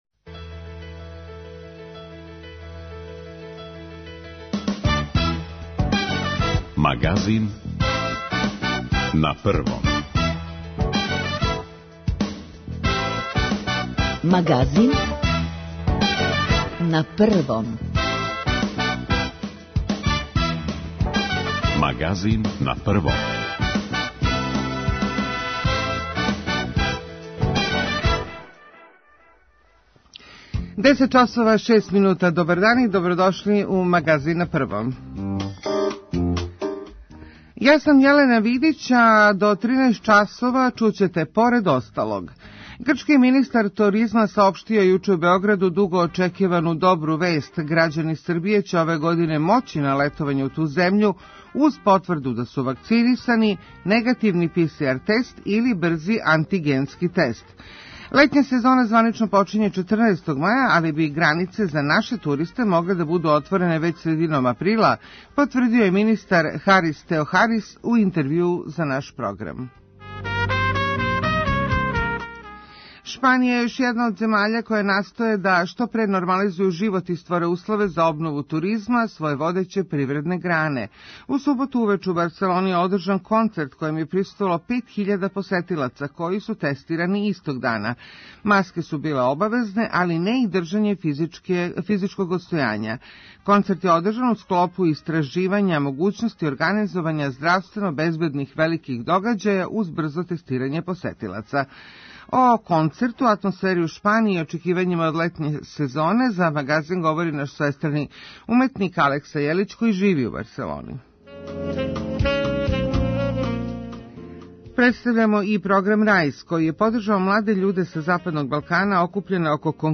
Грчки министар турузма донео је јуче у Београд дуго очекивану добру вест - грађани Србије ће ове године моћи на летовање у Грчку уз потврду да су вакцинисани, негативан Пи-Си-Ар тест или брзи антигенски тест. Летња сезона званично почиње 14-тог маја, али би границе за наше туристе могле да буду отворене већ средином априла, потврдио је министар Харис Теохарис у интервјуу за наш програм.